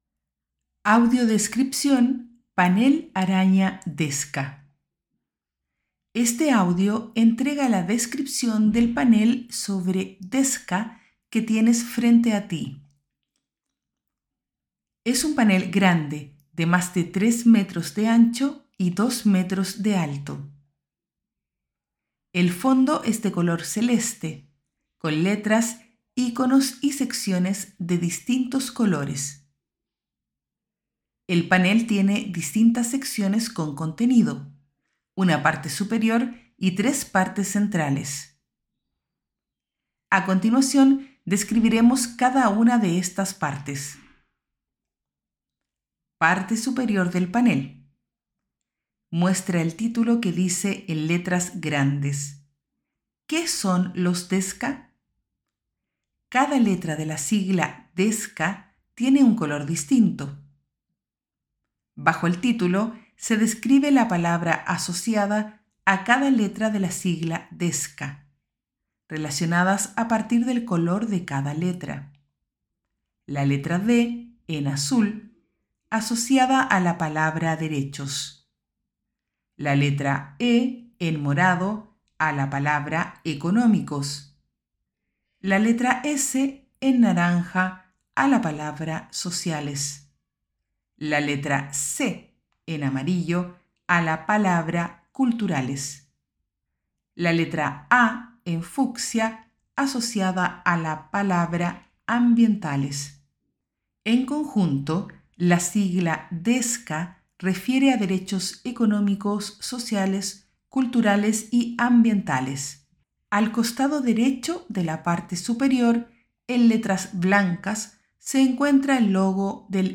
Audiotexto
AUDIODESCRIPCION-Panel-DESCA.mp3